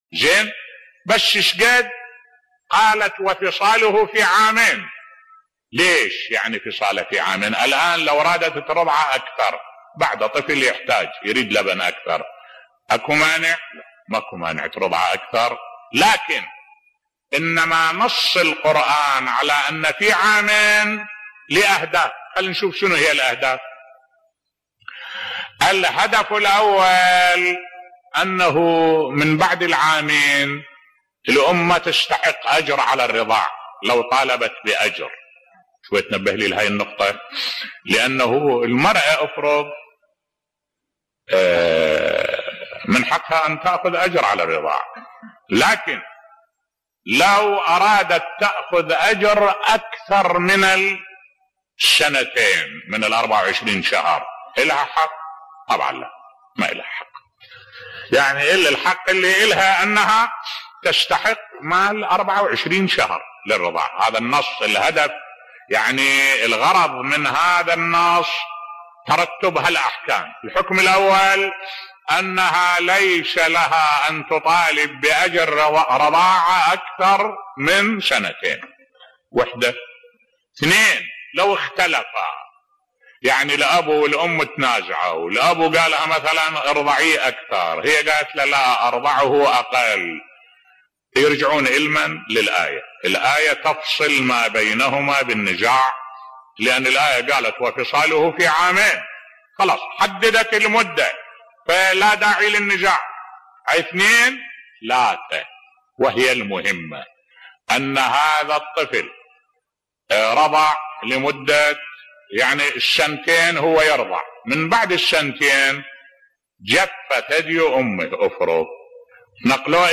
ملف صوتی لماذا حددت الآية رضاع الطفل بمدة عامين فقط بصوت الشيخ الدكتور أحمد الوائلي